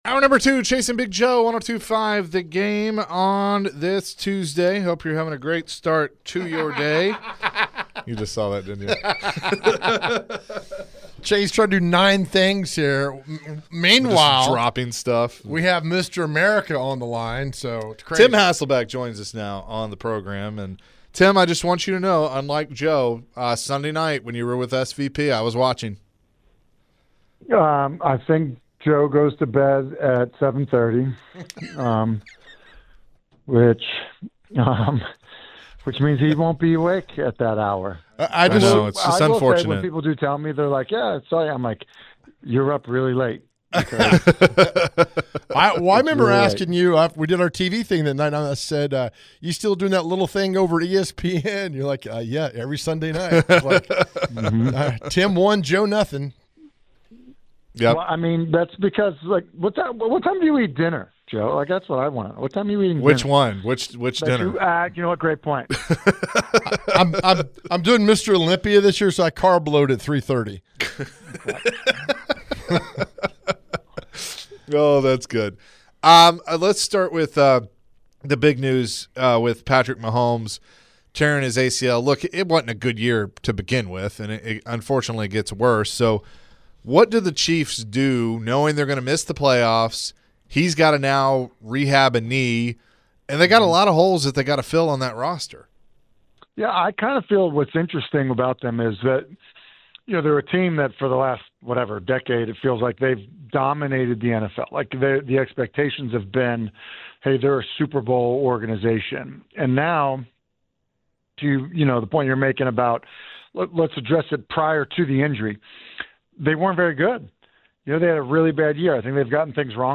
The guys speak with Tim Hasselbeck about the Chiefs, Philip Rivers, and who could be the next Titans head coach.